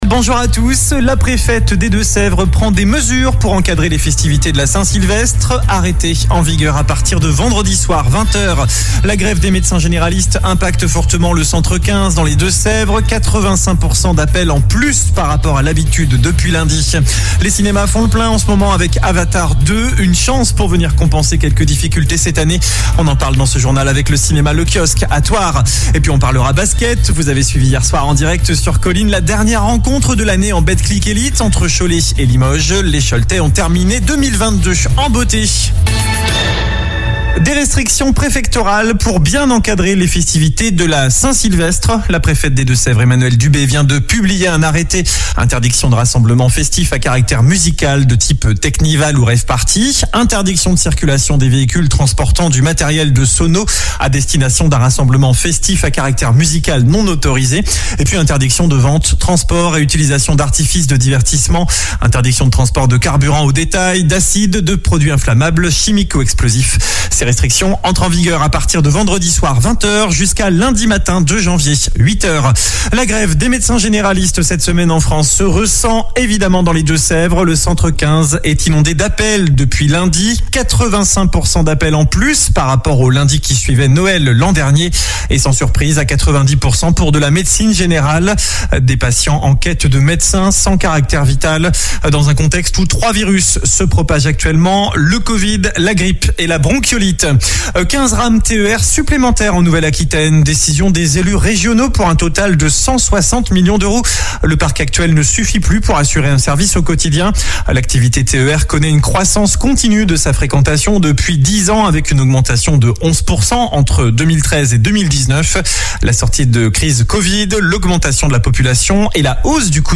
Journal du mercredi 28 décembre